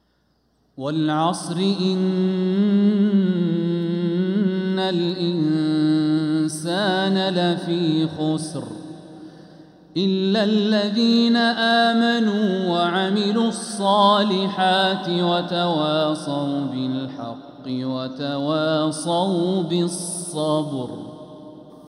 سورة العصر | فروض ربيع الأخر 1446هـ
من الحرم المكي 🕋